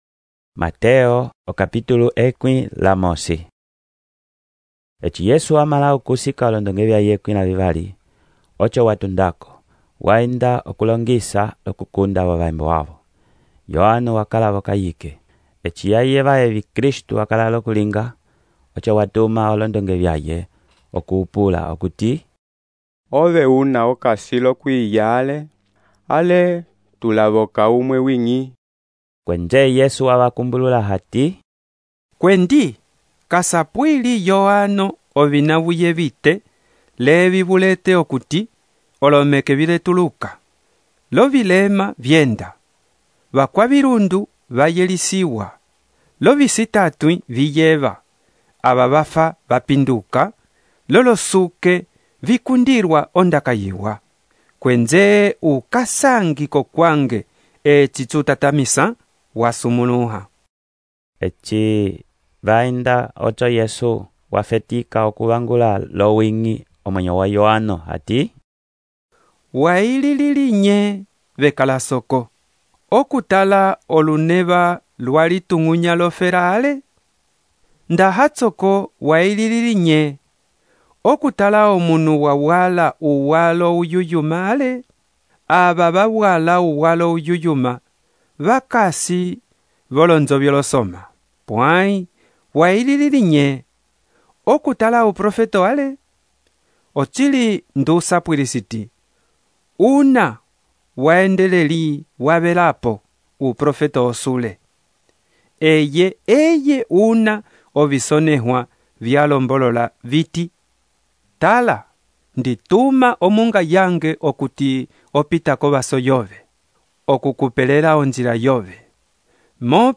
texto e narração , Mateus, capítulo 11